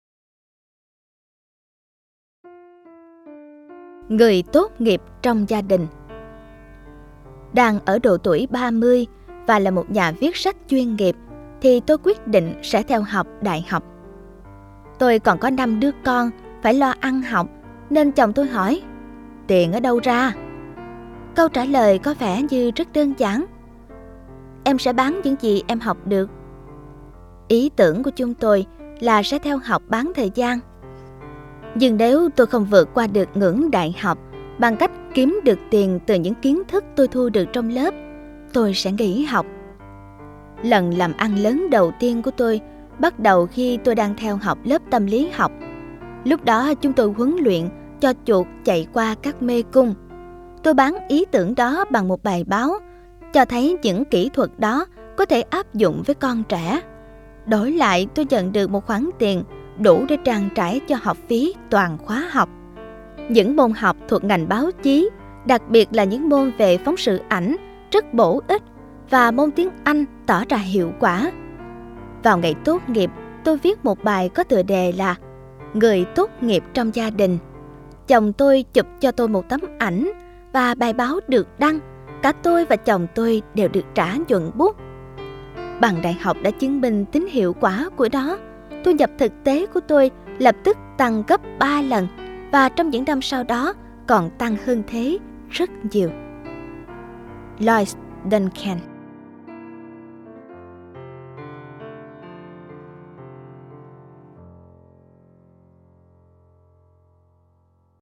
Sách nói Chicken Soup 20 - Hạt Giống Yêu Thương - Jack Canfield - Sách Nói Online Hay